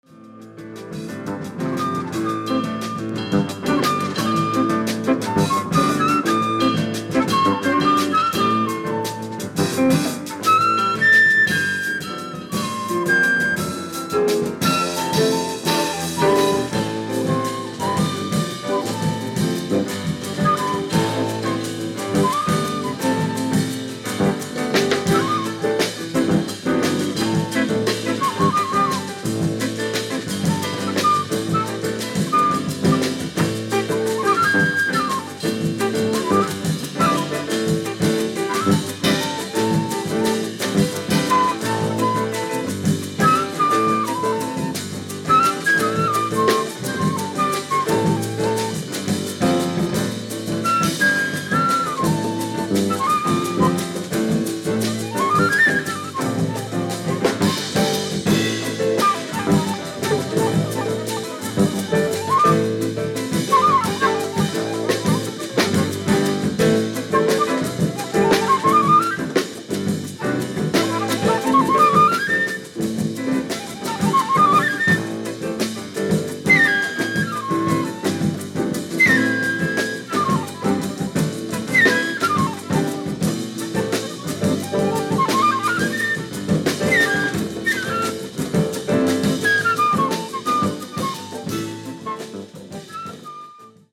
Saxophone, Flute